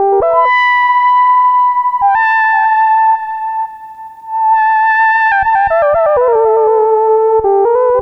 Synth 09.wav